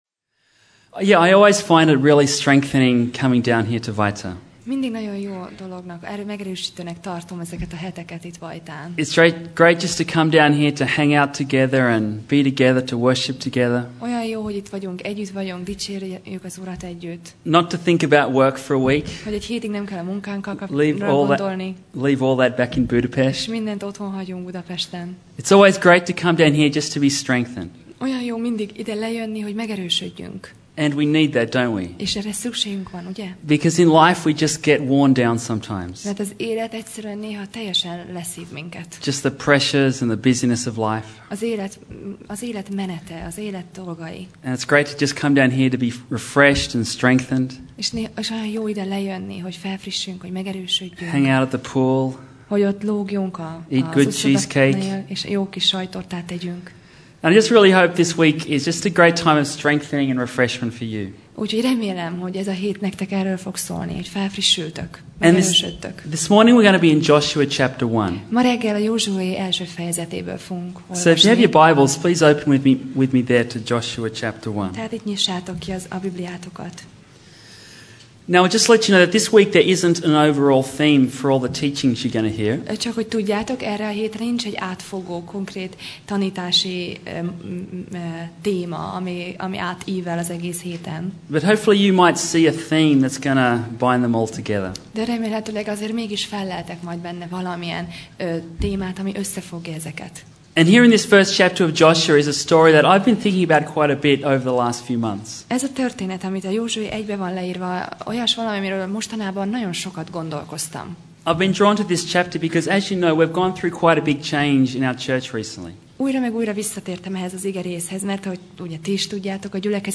Alkalom: Konferencia